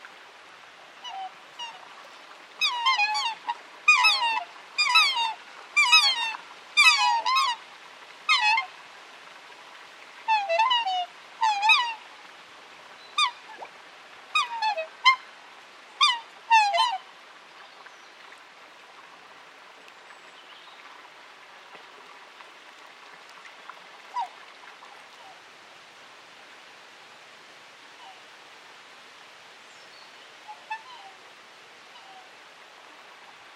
Cygne noir - Mes zoazos